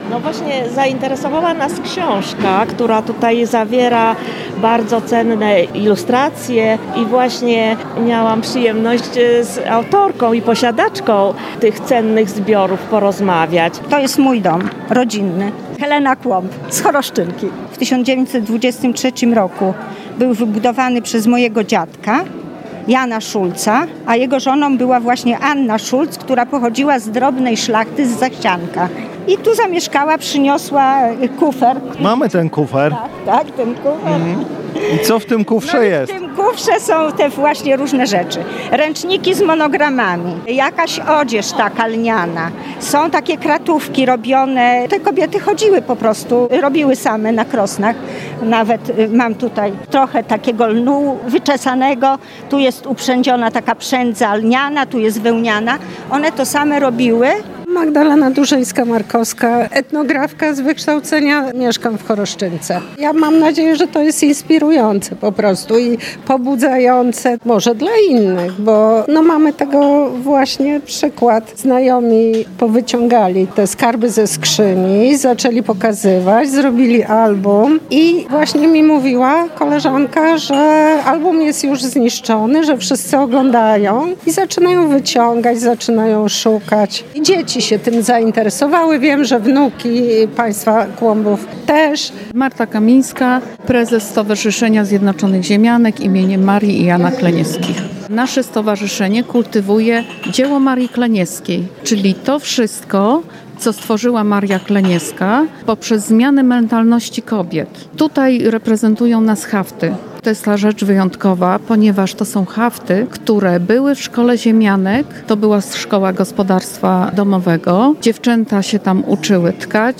Unikalne zwyczaje, praktyki i umiejętności przekazywane z pokolenia na pokolenie prezentowane były wczoraj w Nałęczowie. Zorganizowano tam II Zjazd Pasjonatów Ochrony Niematerialnego Dziedzictwa.